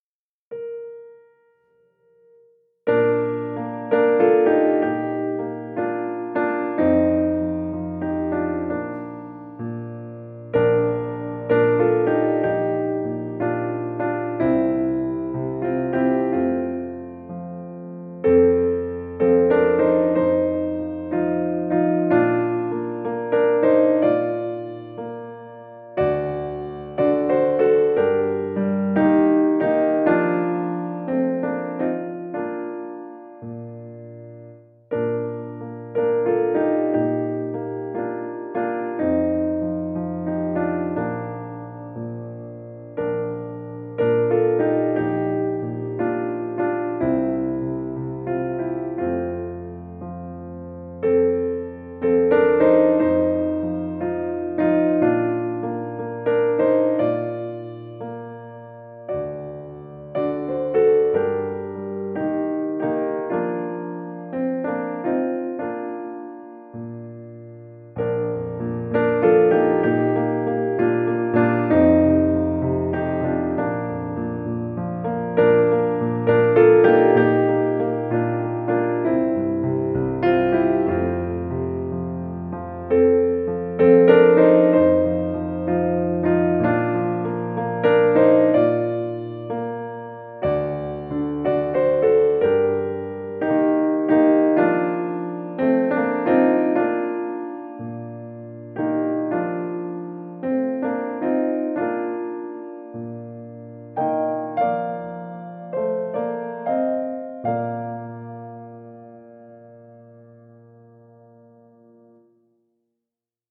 Drüsen- und Harmonieübung:
Zur Übung wird oft nur die erste Zeile des Liedes gesungen und 4 x wiederholt.
a) Text: O.Z.A. Hanish, Melodie: P.P. Bliss, Lied-Erstv. in D 1930, in US 1917